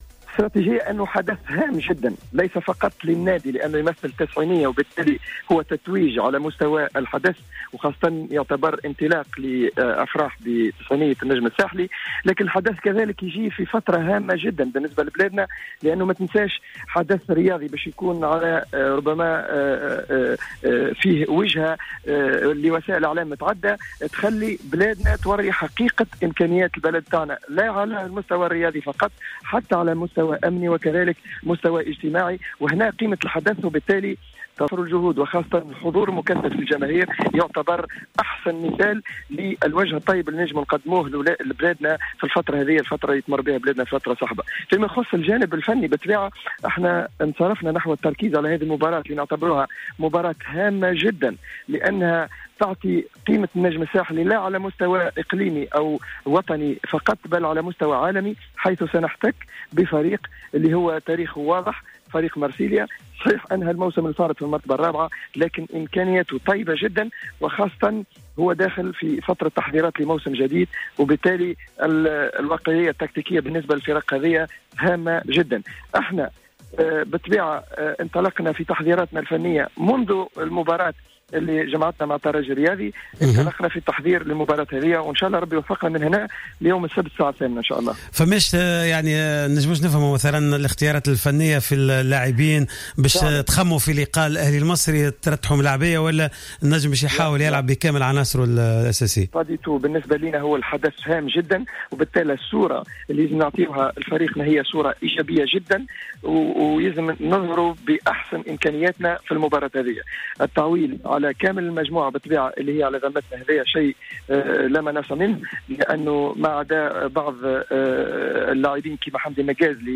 مداخلة على جوهرة أف أم